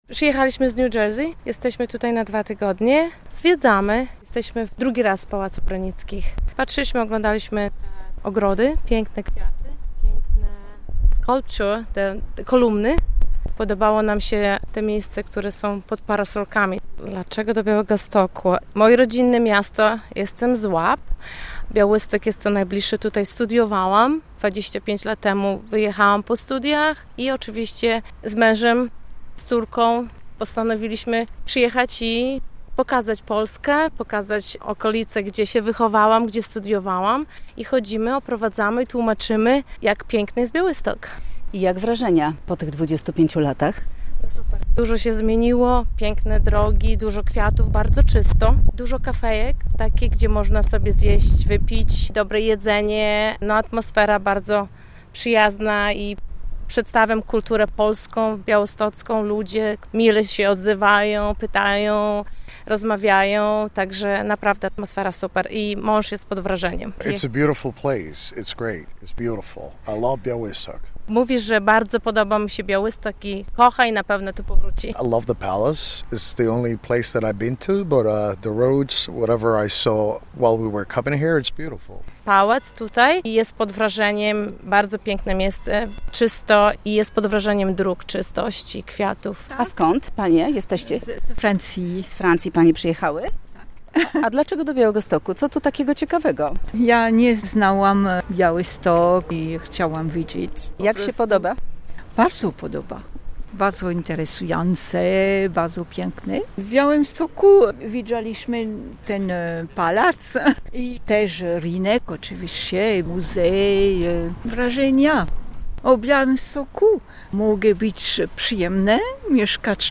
Turyści w Białymstoku - relacja